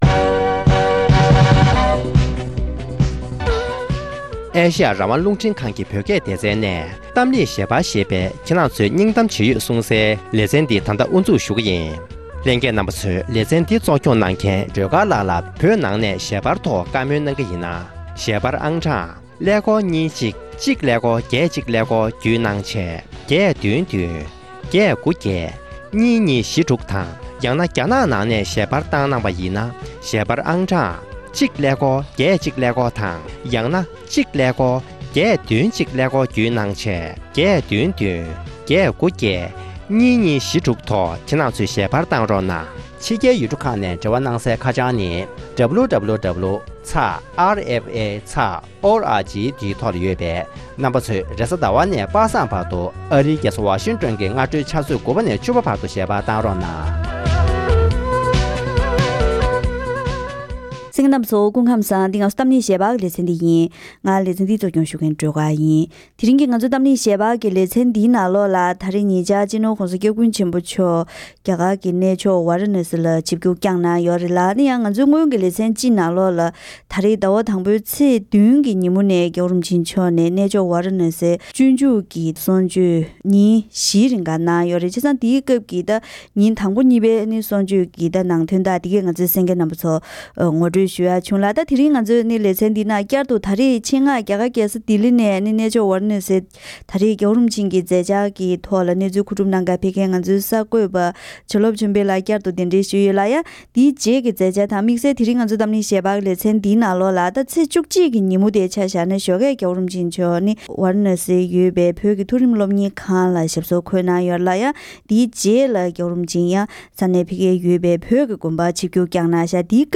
༸གོང་ས་མཆོག་ནས་སར་ནཐ་བོད་དགོན་པར་བཀའ་སློབ།